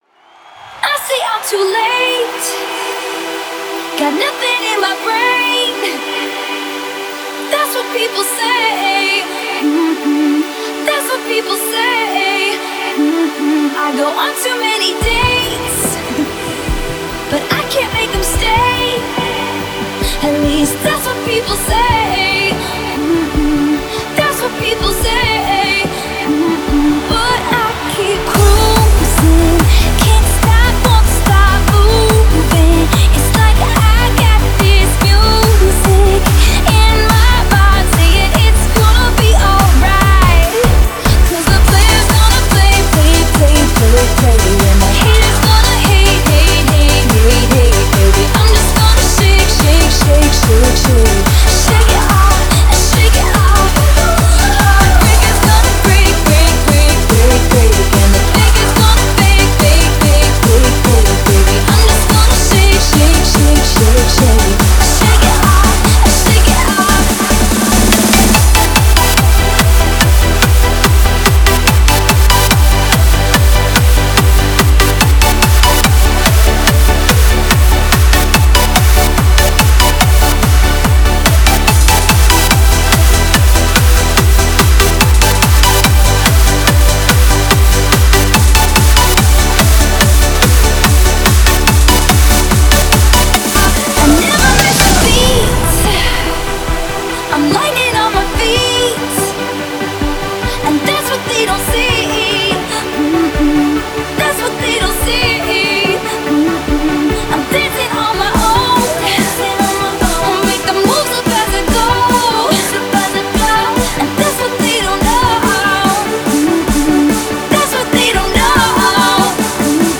a Hands Up song